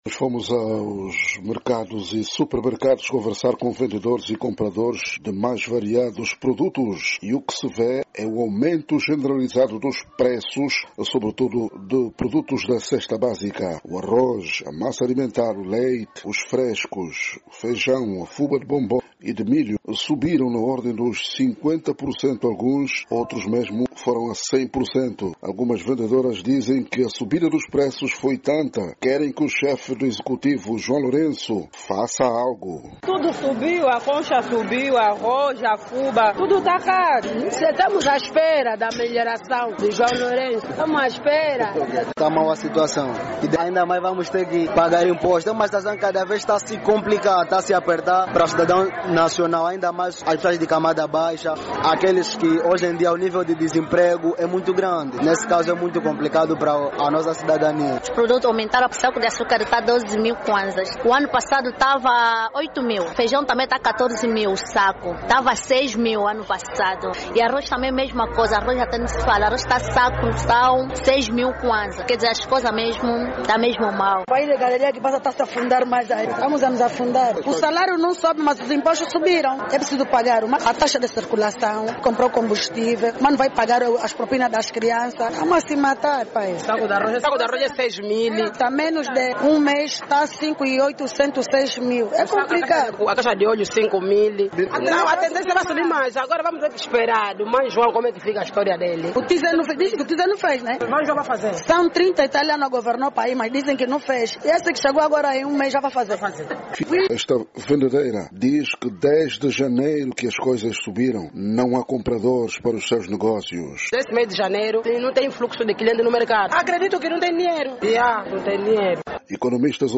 Comusidores e venderoes reagem à subida depreços em Luanda - 2:08
A reportagem da Voz da América verificou o aumento generalizado dos preços sobretudo aqueles da cesta básica.